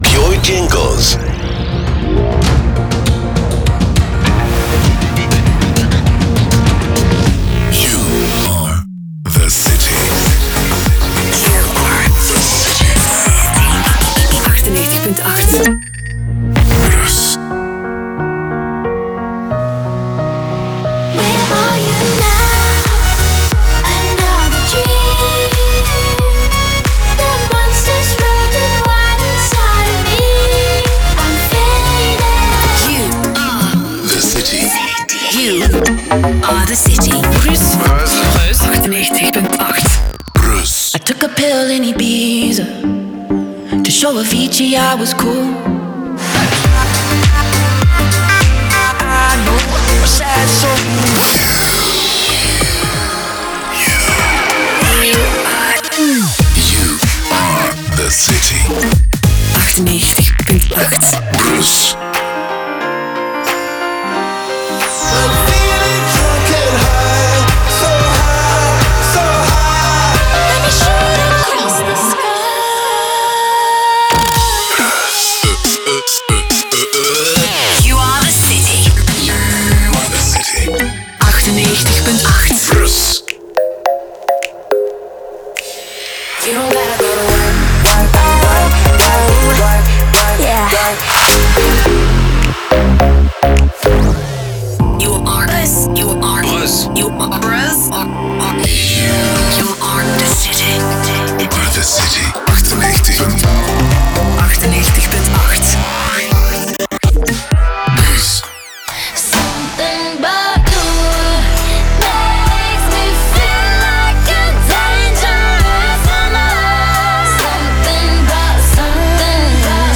créer un son à base de voix off-rythmique